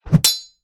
Hiệu ứng âm thanh Tiếng thanh Kiếm chém vào kim loại - Tải Mp3